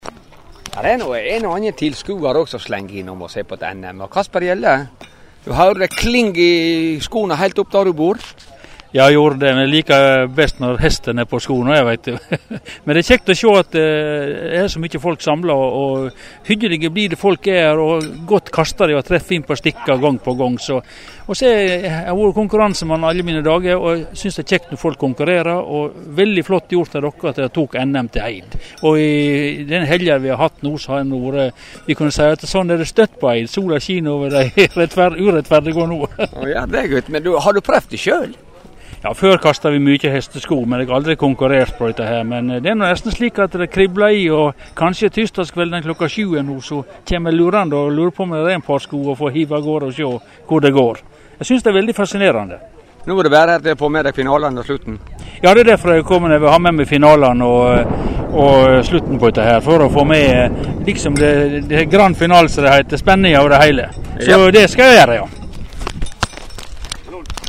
Lydklipp frå NM i Hesteskokasting
Intervju